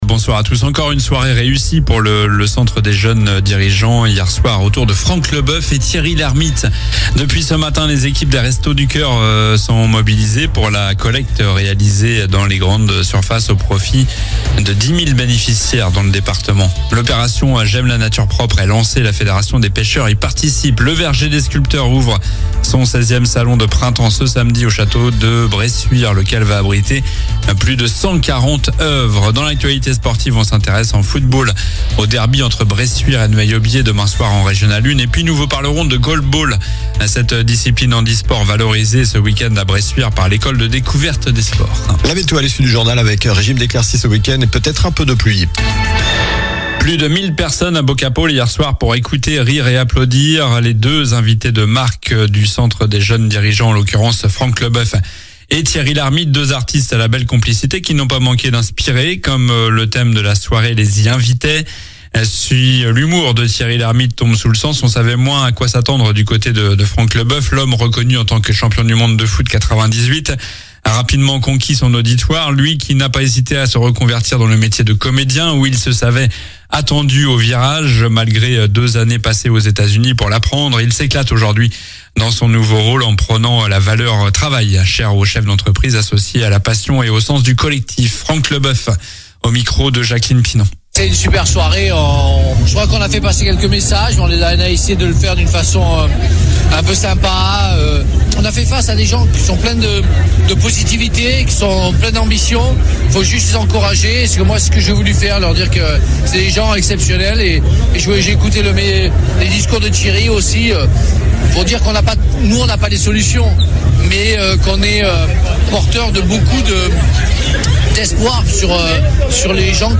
Journal du vendredi 06 mars (soir)